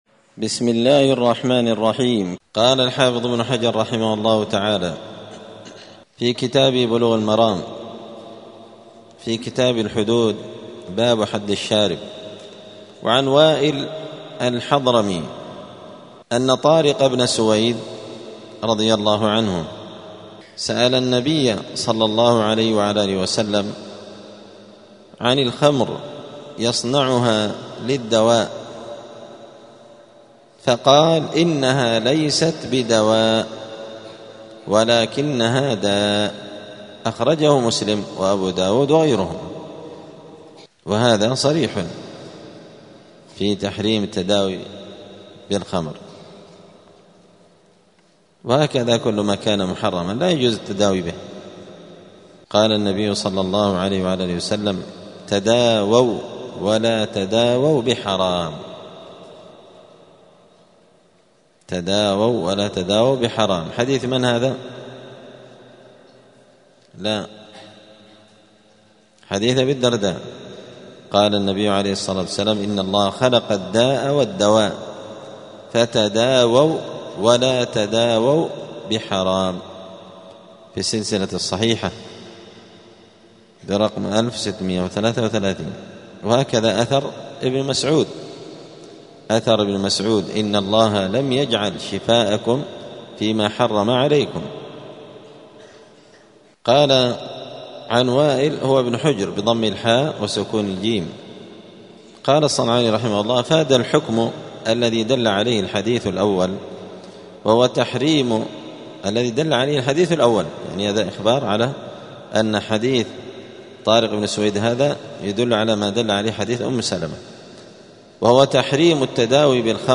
*الدرس السابع والثلاثون (37) {باب التعزير}*